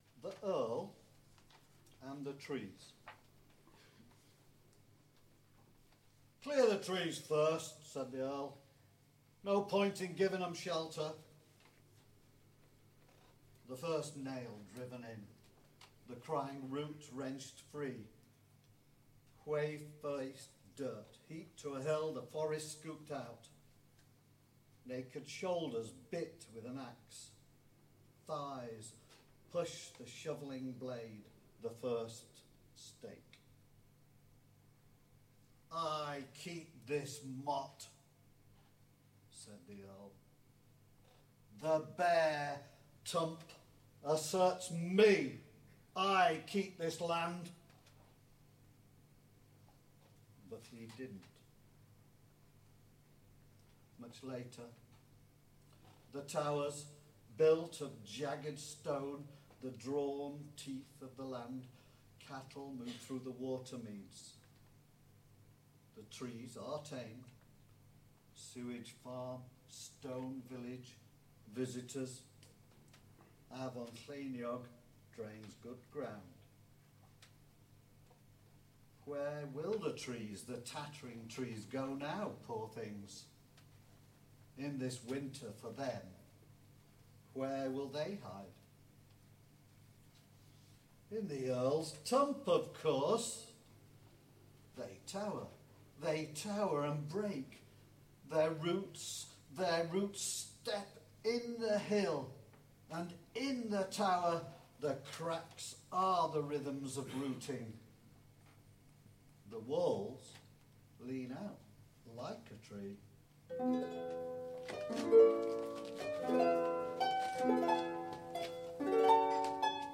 ‘Tony Conran Remembered’ performed at Another Festival, Caernarfon July 2015